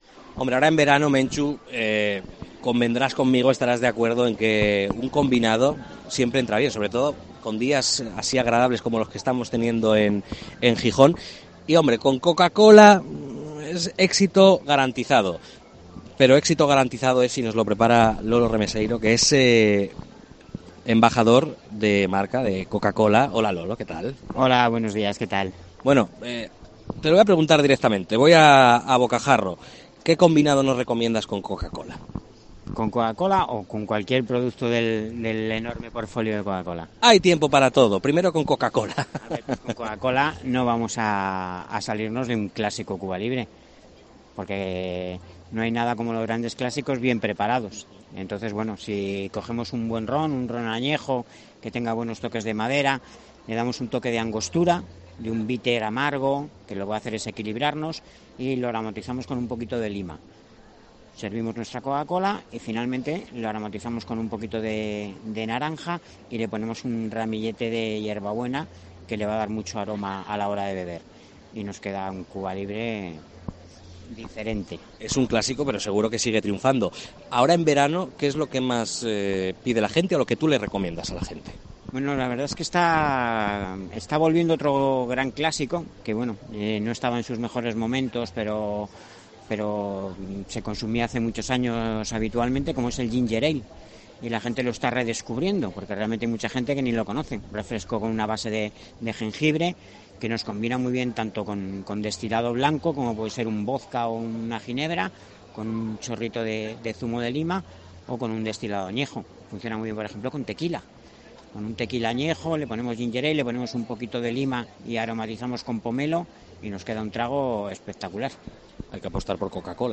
Hemos hablado en el especial de COPE desde la FIDMA
FIDMA 2023: entrevista